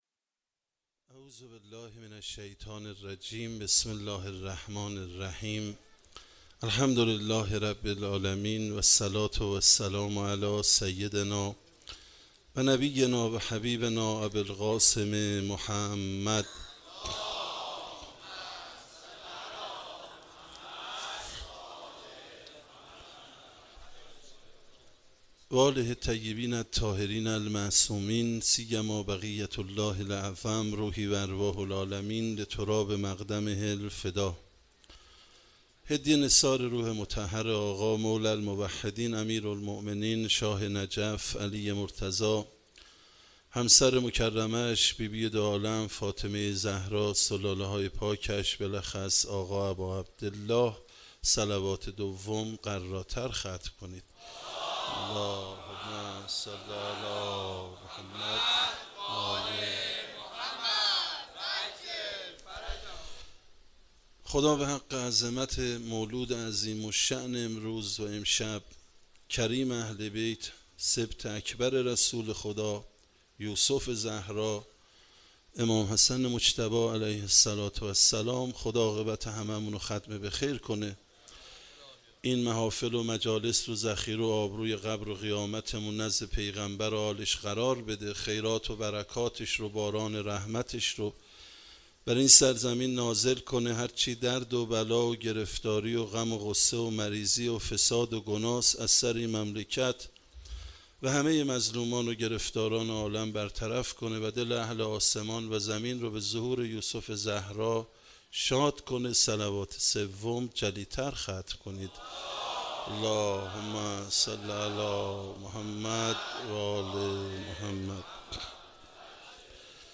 شب 15 ماه رمضان_هیئت مکتب الزهرا سلام الله علیها
سخنرانی